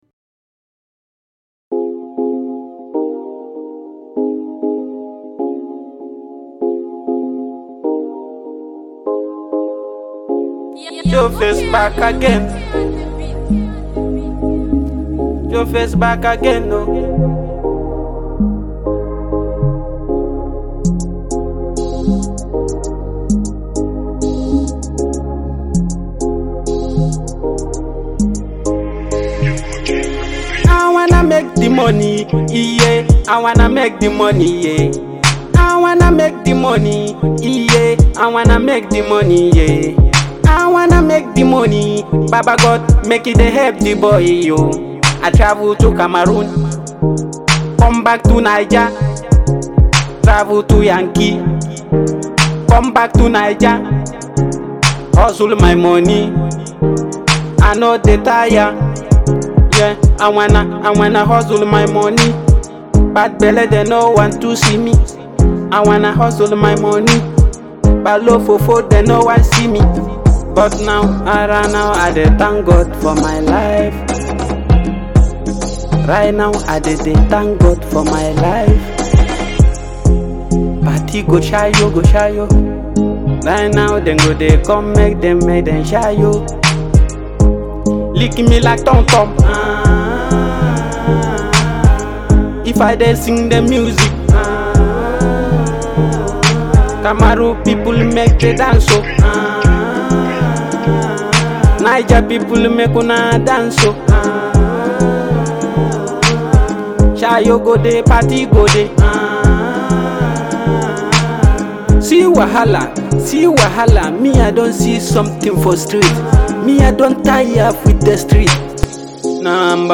afro singer
afropop artist